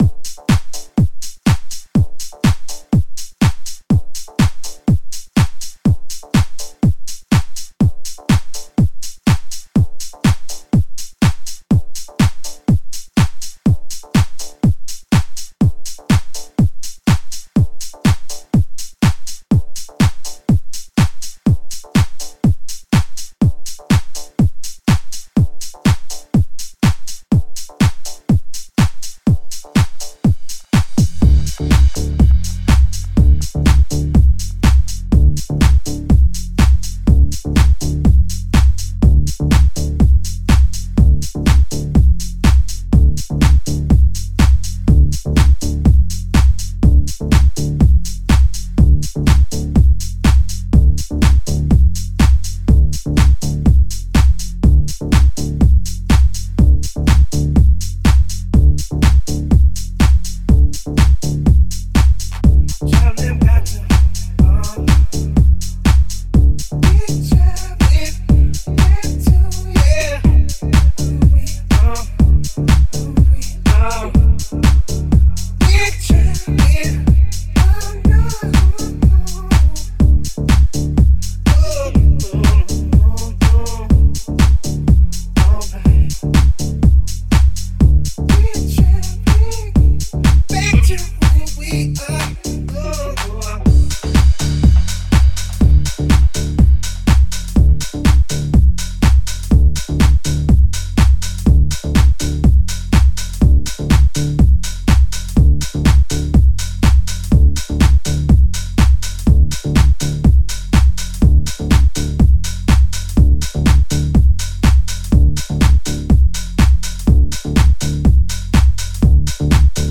Beat Programming
Das Tempo liegt bei 123 BPM.
House-Kick